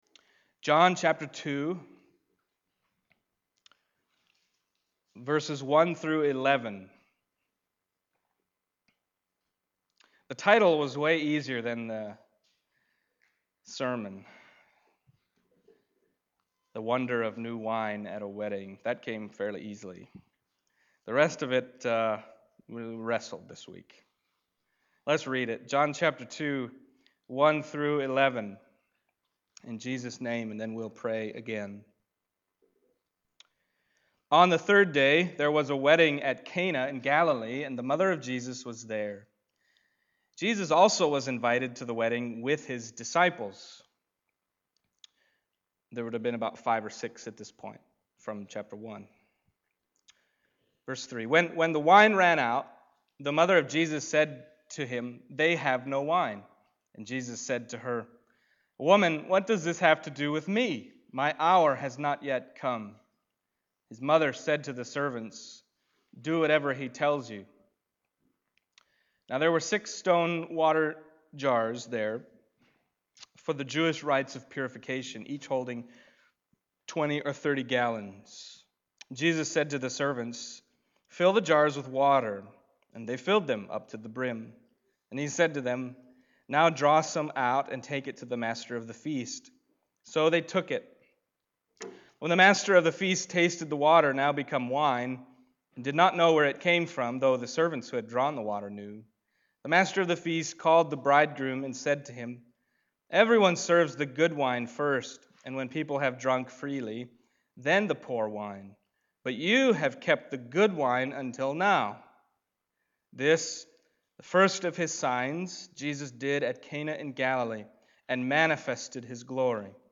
Passage: John 2:1-11 Service Type: Sunday Morning John 2:1-11 « Messiah